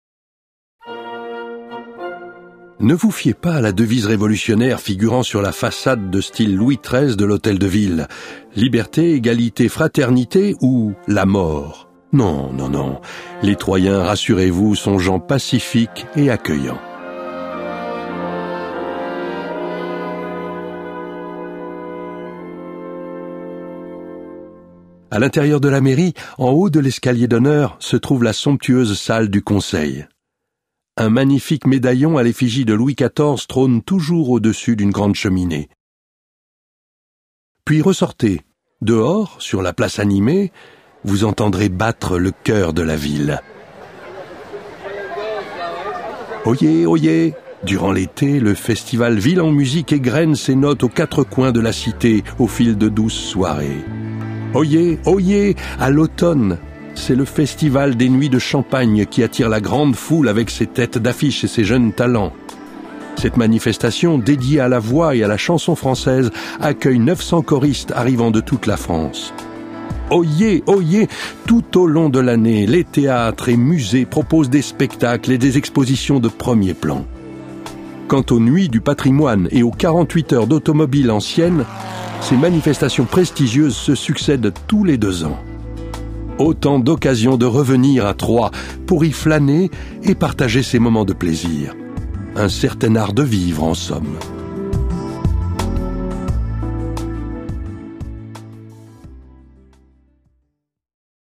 Cette balade audio-guidée vous permet de découvrir par vous-même tous les lieux importants de la ville de Troyes, tout en bénéficiant des explications de votre guide touristique numérique.